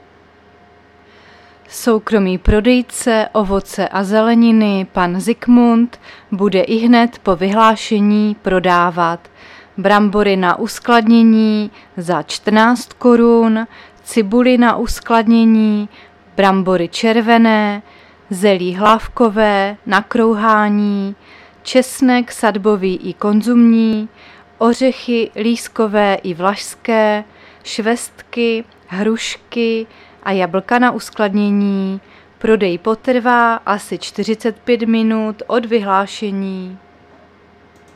Záznam hlášení místního rozhlasu 24.10.2023
Zařazení: Rozhlas